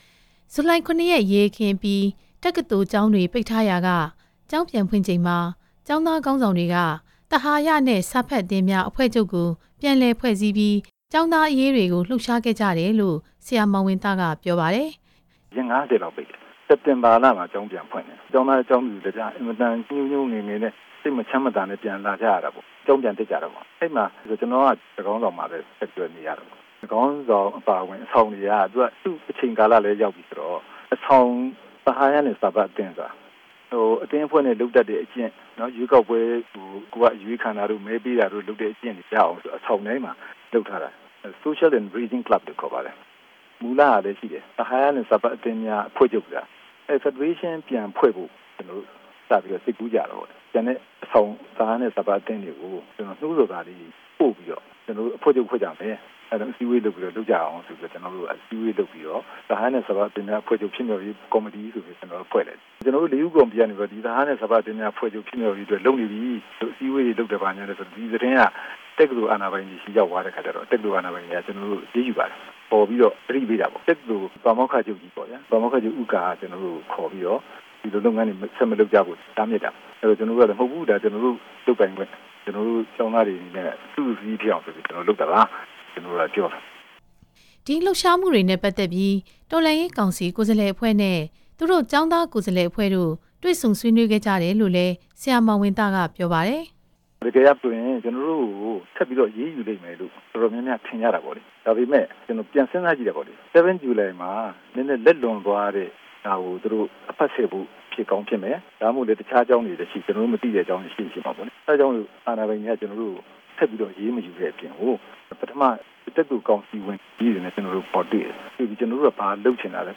၁၉၆၂ခု ဇူလိုင် ၇ ရက် ကျောင်းသား အရေးတော်ပုံ ကိုယ်တွေ့ ကြုံခဲ့သူများရဲ့ ပြောပြချက်